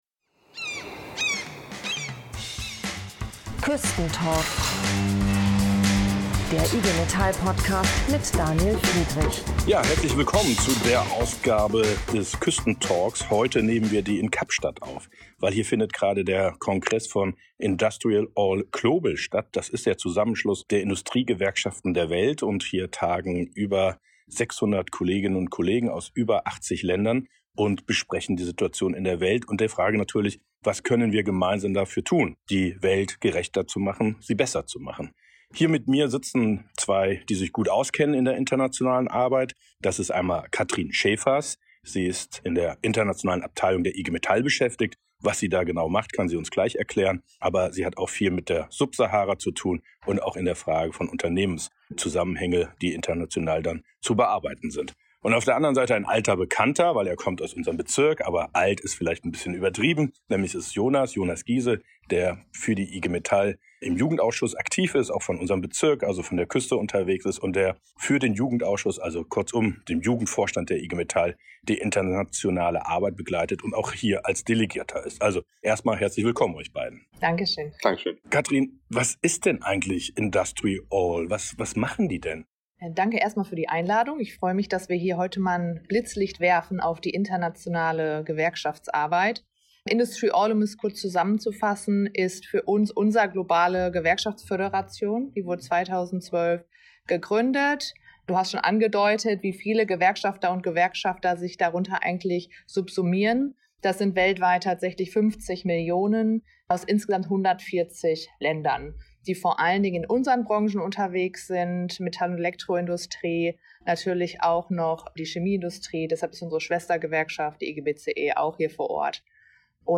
Beschreibung vor 2 Jahren Der Küstentalk Nr. 28 ist diesmal weitgereist und in Kapstadt aufgezeichnet worden (entschuldigt kleine Fehler in der Tonqualität).